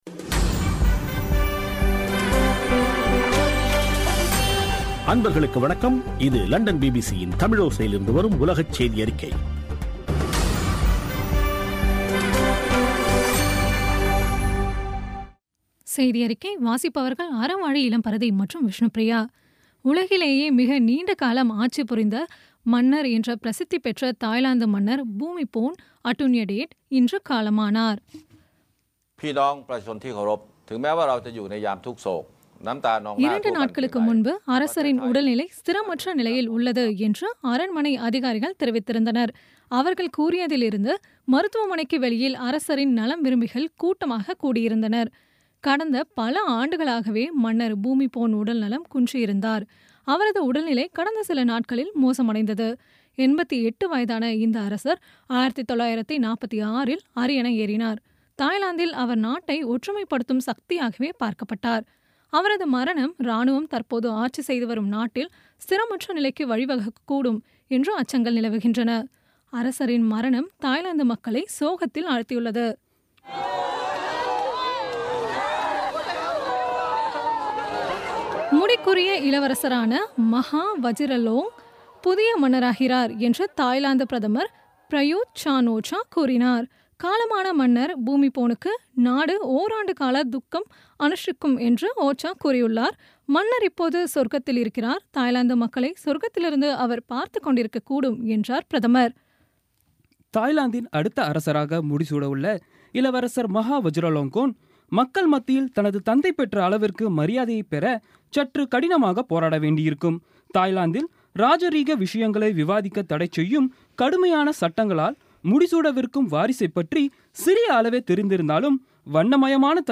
இன்றைய (அக்டோபர் 13ம் தேதி) பிபிசி தமிழோசை செய்தியறிக்கை